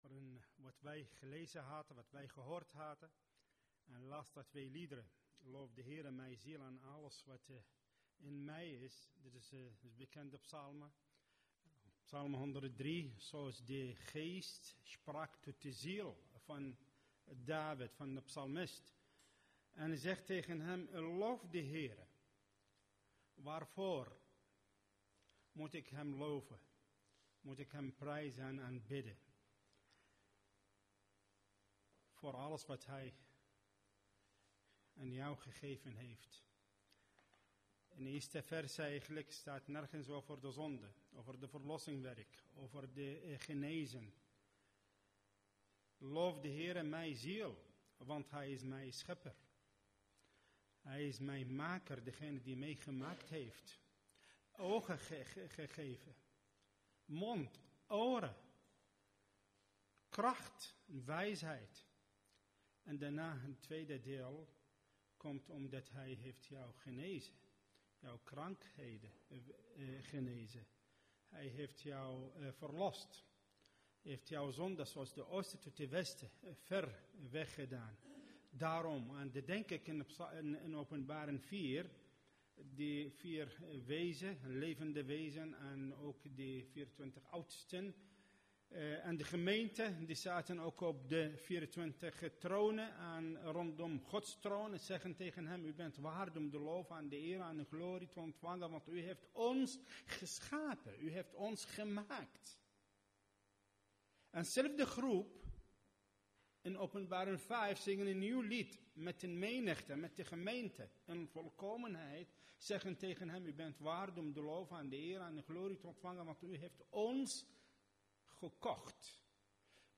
Preek zondag 2 november 2014